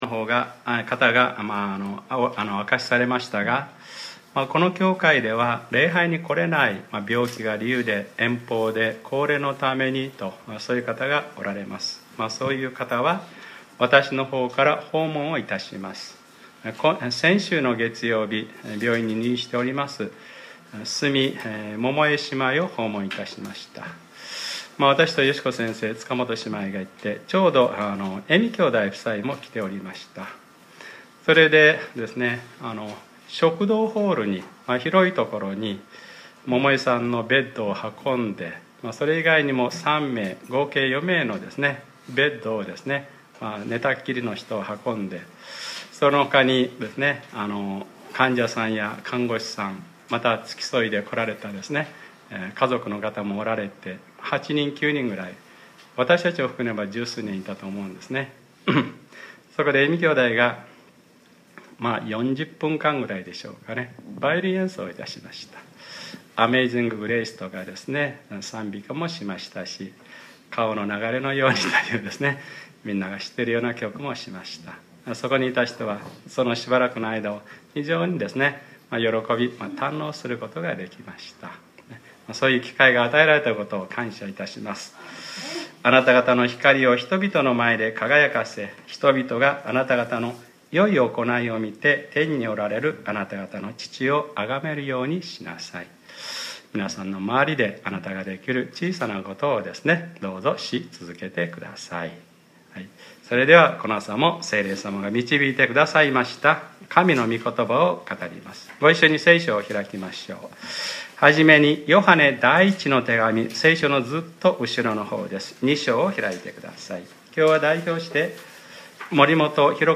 2015年07月26日(日）礼拝説教 『Ⅰヨハネｰ６：キリストのうちにとどまっていなさい』